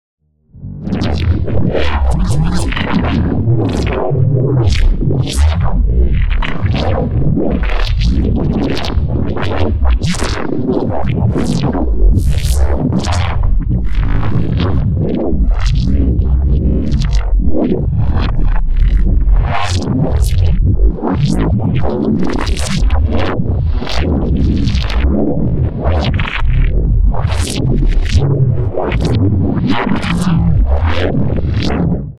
Weird neuro fx pt.2.wav